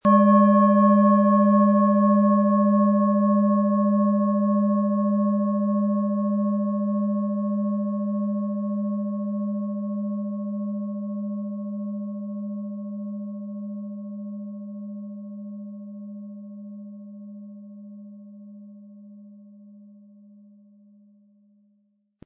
Planetenton 1
Es ist eine von Hand gearbeitete tibetanische Planetenschale Uranus.
SchalenformBihar
MaterialBronze